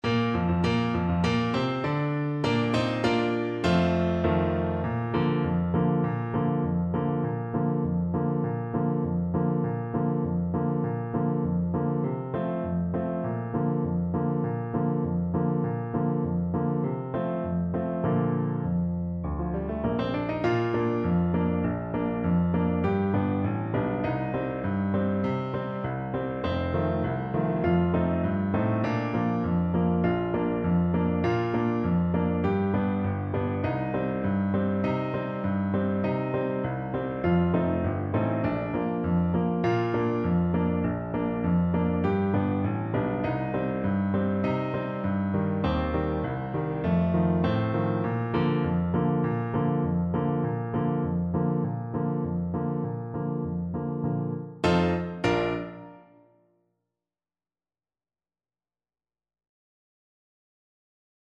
French Horn
Bb major (Sounding Pitch) F major (French Horn in F) (View more Bb major Music for French Horn )
2/4 (View more 2/4 Music)
With energy =c.100
Classical (View more Classical French Horn Music)
Mexican